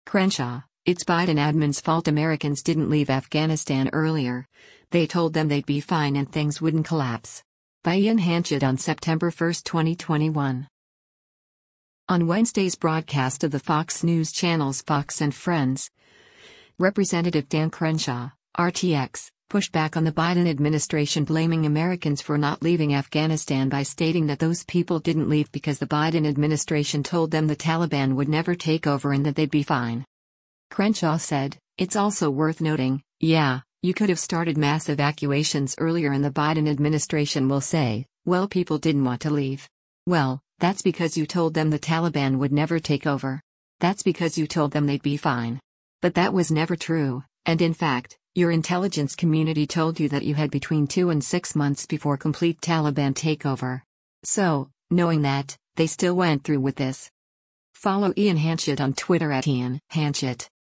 On Wednesday’s broadcast of the Fox News Channel’s “Fox & Friends,” Rep. Dan Crenshaw (R-TX) pushed back on the Biden administration blaming Americans for not leaving Afghanistan by stating that those people didn’t leave because the Biden administration “told them the Taliban would never take over” and that “they’d be fine.”